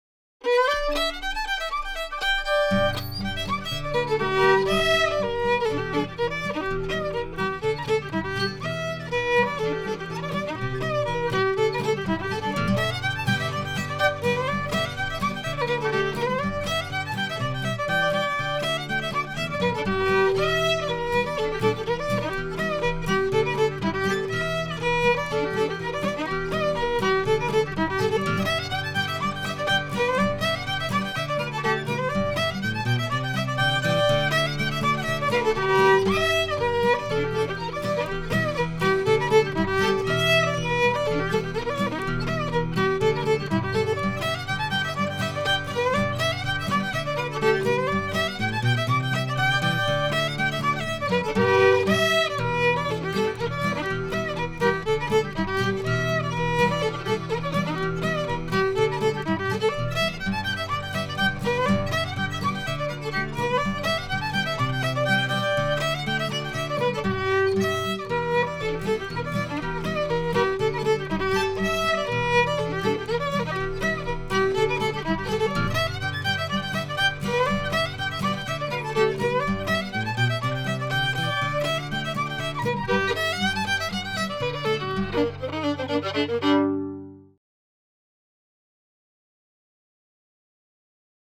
This old English carol has undergone many changes.